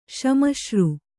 ♪ sśmaśru